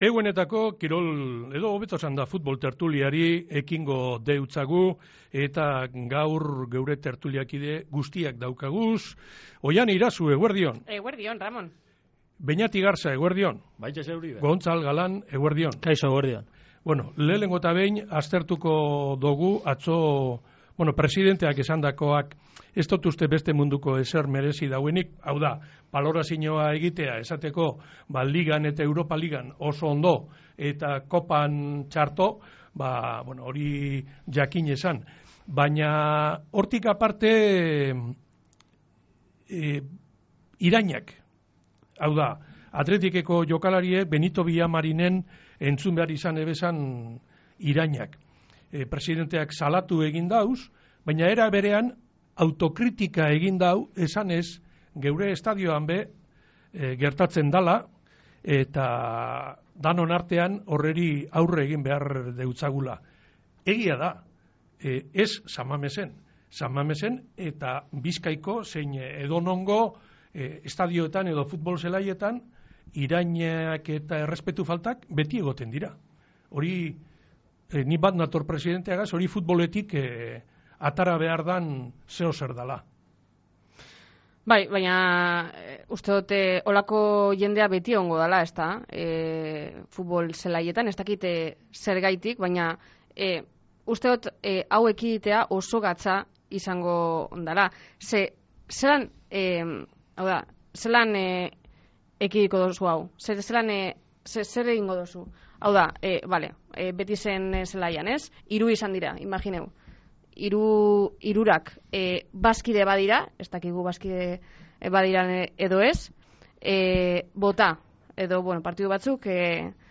Eguenero egiten dogu Athleticen ganeko tertulia irratian, talde zuri-gorriaren gaur egungo gaiak aztertzeko